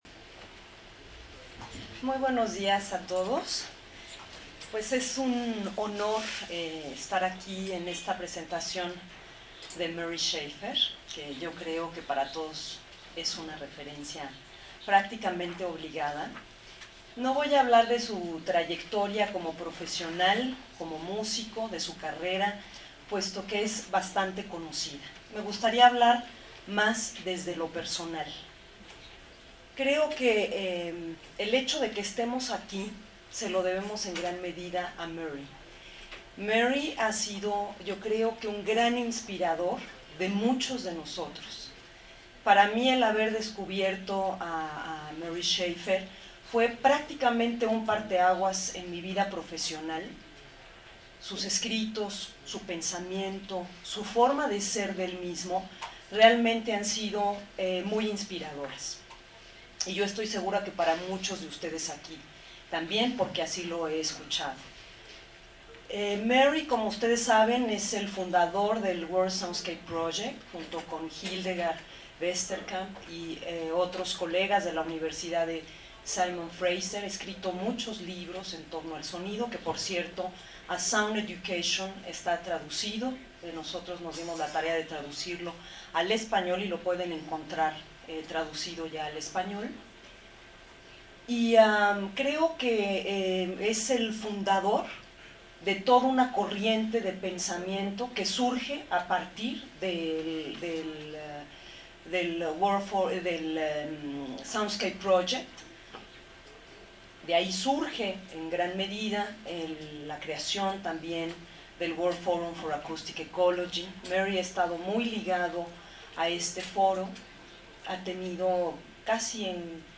Mi ponencia la titulé con el nombre de “Nunca vi un sonido”: